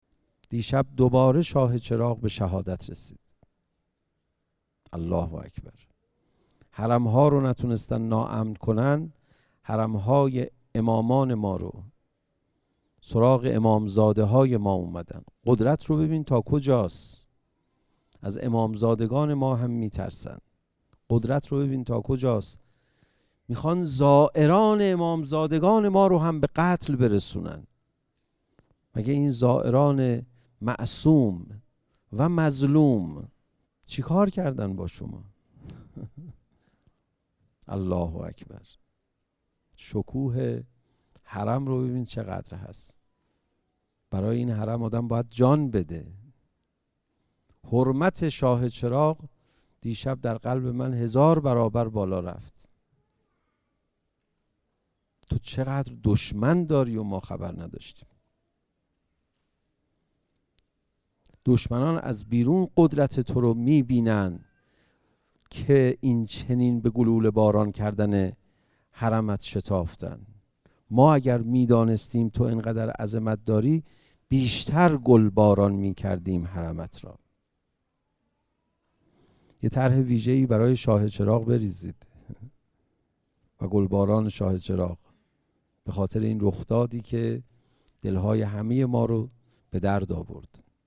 منبع: تهران، مجموعۀ ورزشی 5 آذر